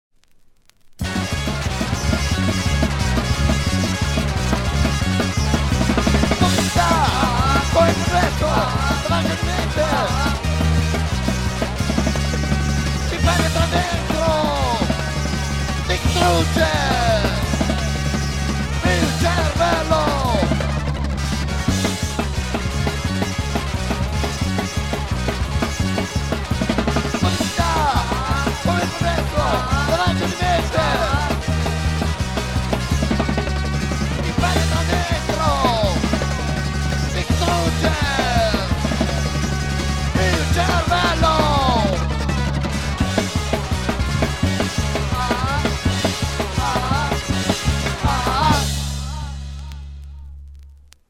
• Centro Sportivo di Lobbi (AL)